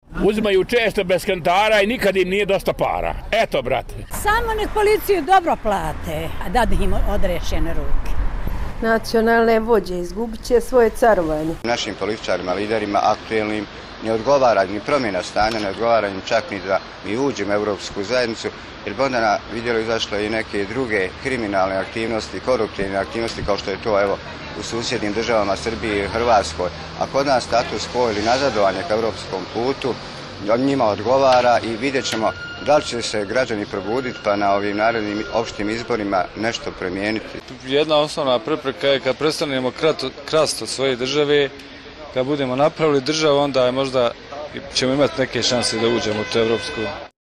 Građani o političarima